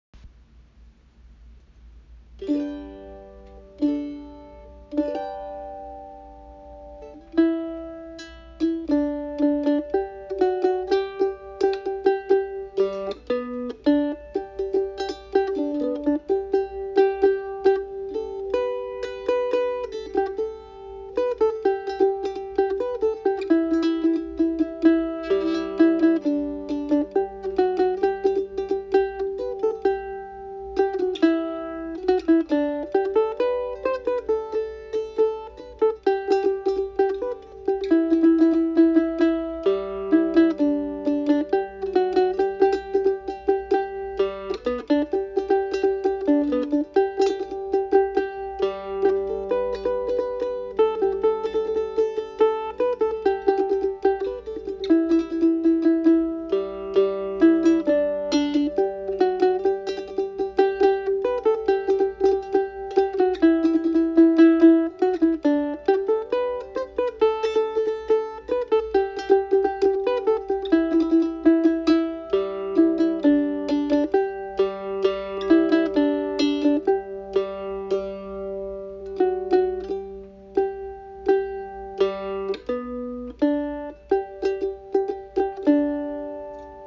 Are you washed in the blood on my mandolin